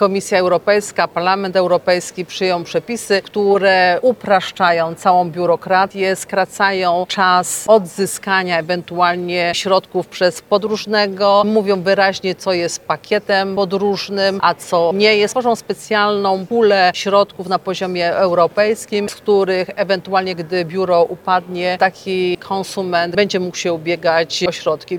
Myślę, że i biura i przede wszystkim konsumenci czekali na te jasne wytyczne – mówi Elżbieta Łukacijewska, posłanka do Parlamentu Europejskiego: